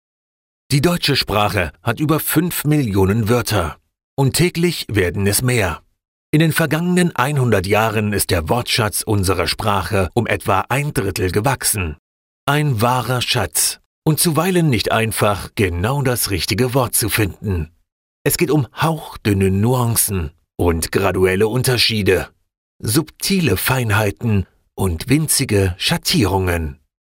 A modern, warm and dynamic voice equally at home in his native German or english
Documentary
informative, Bright, Upbeat, Natural